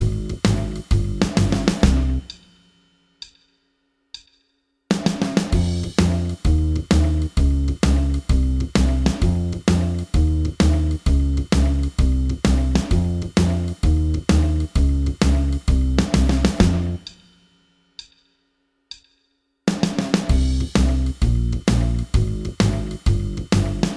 Minus Guitars Rock 'n' Roll 3:21 Buy £1.50